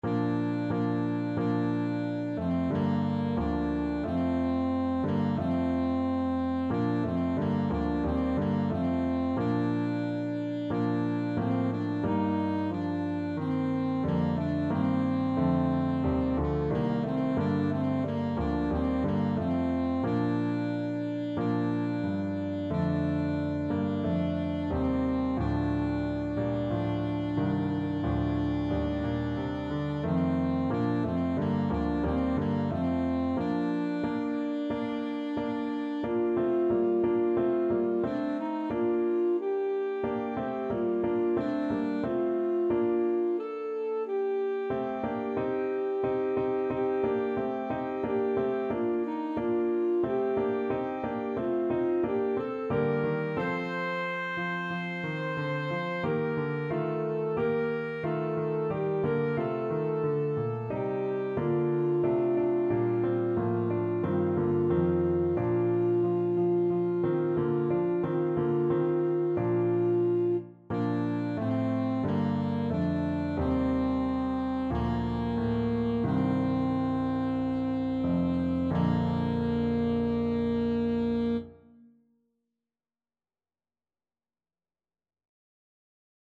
Alto Saxophone version
Alto Saxophone
4/4 (View more 4/4 Music)
A4-C6
Classical (View more Classical Saxophone Music)